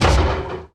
Minecraft Version Minecraft Version snapshot Latest Release | Latest Snapshot snapshot / assets / minecraft / sounds / mob / irongolem / hit2.ogg Compare With Compare With Latest Release | Latest Snapshot